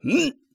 ZS格挡1.wav
ZS格挡1.wav 0:00.00 0:00.55 ZS格挡1.wav WAV · 48 KB · 單聲道 (1ch) 下载文件 本站所有音效均采用 CC0 授权 ，可免费用于商业与个人项目，无需署名。
人声采集素材/男3战士型/ZS格挡1.wav